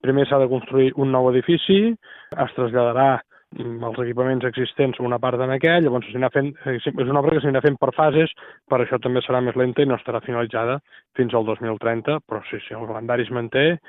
L’alcalde indica que les obres s’executaran per fases per poder mantenir el màxim d’activitat.